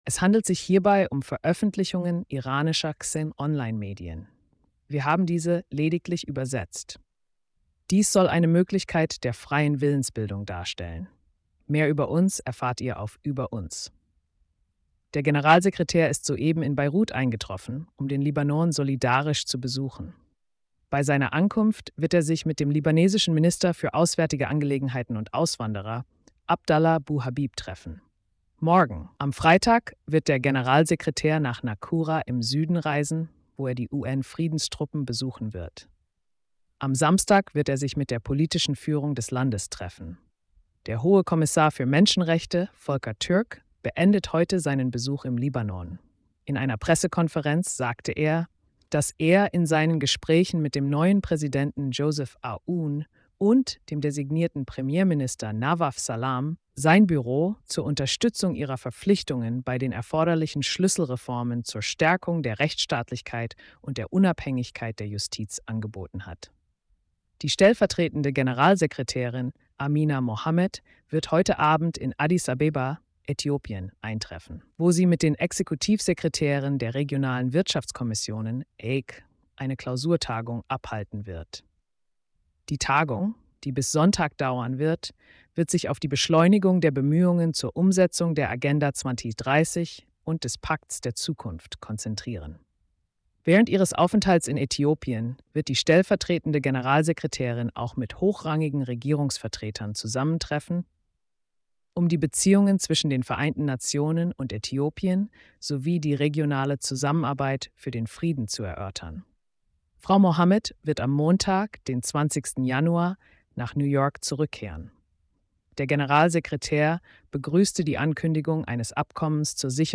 Tägliche Pressekonferenz: Aktuelle Themen aus dem Nahen Osten – UN (16. Januar 2025)